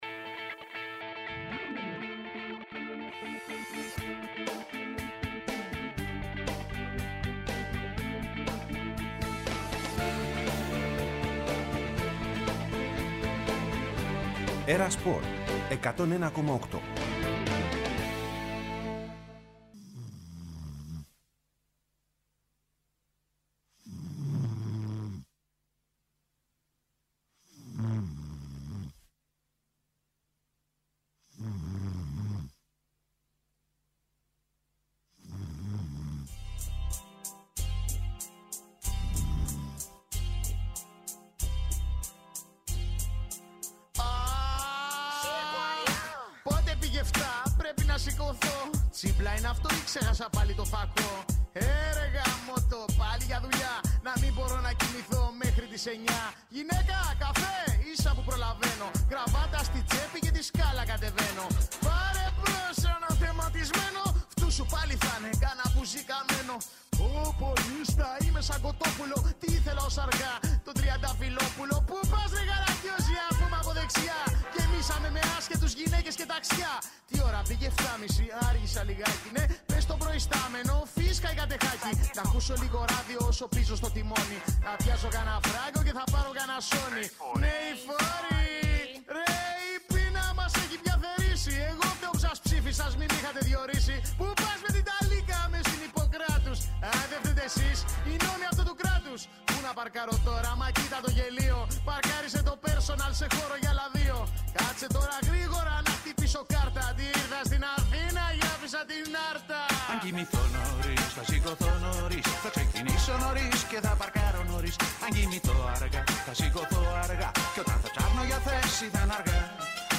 Συνεντεύξεις και ρεπορτάζ για όσα συμβαίνουν εντός και εκτός γηπέδων.